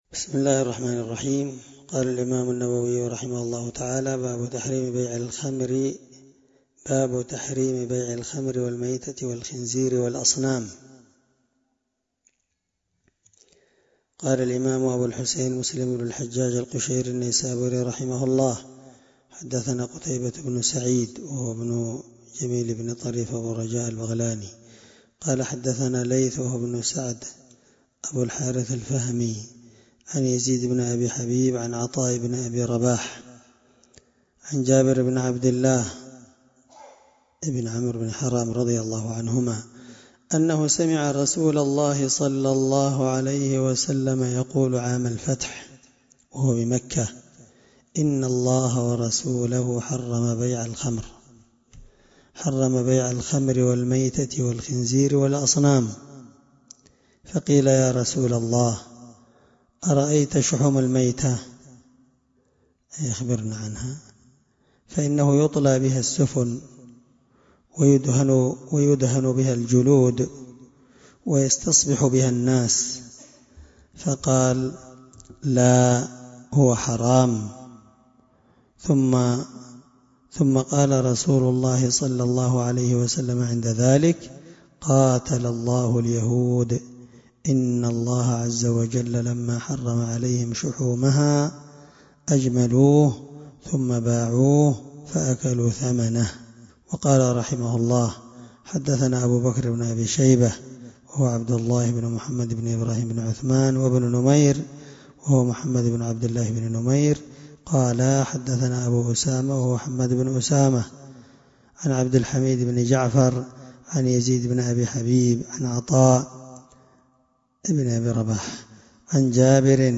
الدرس15من شرح كتاب المساقاة حديث رقم(1781-1583) من صحيح مسلم